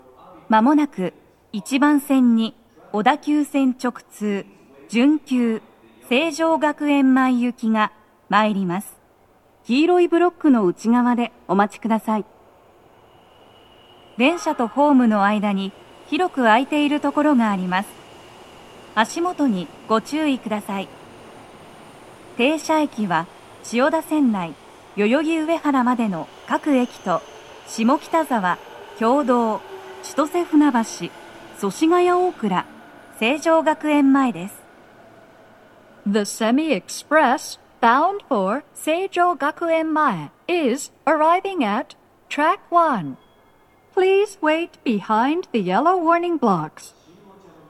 スピーカー種類 BOSE天井
鳴動は、やや遅めです。
女声
接近放送3